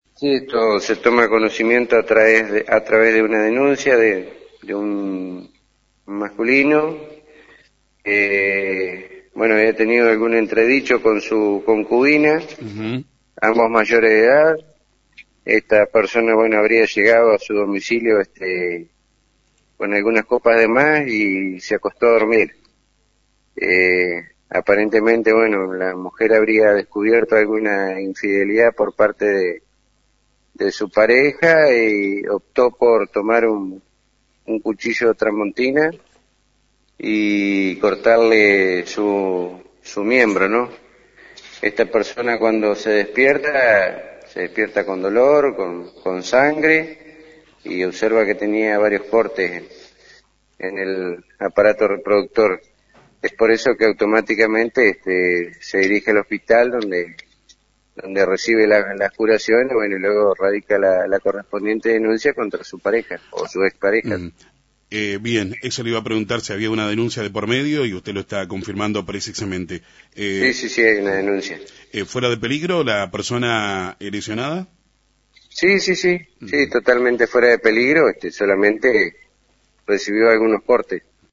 En las primeras horas de este domingo, una mujer se presentó en la Policía para denunciar que con un cuchillo “tramontina” le cortó parte del miembro a su pareja. En la mañana de FM 90.3